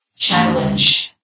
sound / vox_fem / challenge.ogg
CitadelStationBot df15bbe0f0 [MIRROR] New & Fixed AI VOX Sound Files ( #6003 ) ...